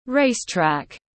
Đường đua tiếng anh gọi là racetrack, phiên âm tiếng anh đọc là /ˈreɪstræk/ .
Racetrack /ˈreɪstræk/